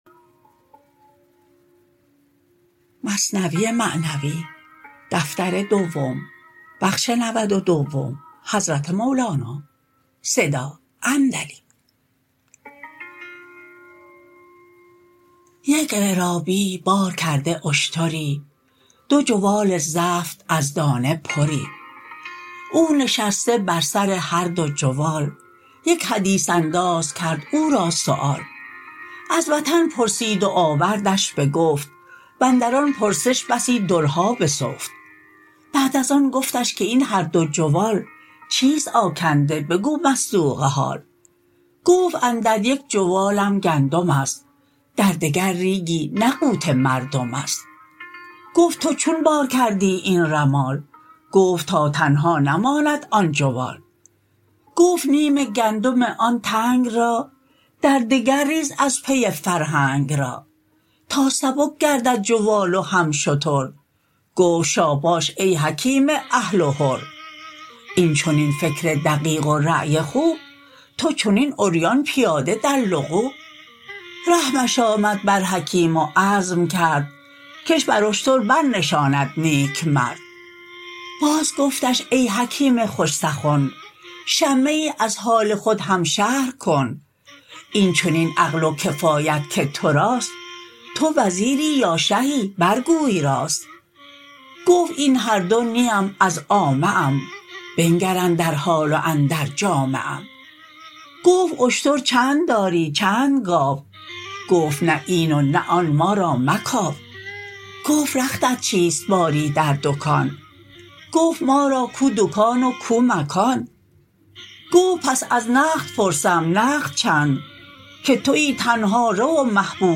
متن خوانش: